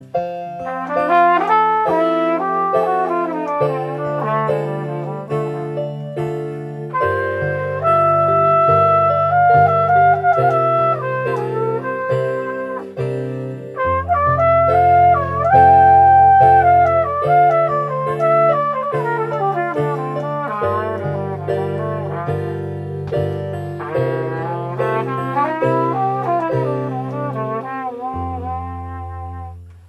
Like on violin I prefer the low range with an airy sound. The higher range I play with minimal pressure and never forceful. My lips are not too airtight, so you hear kinda sexy vocal sissle.
jazz-trumpet.mp3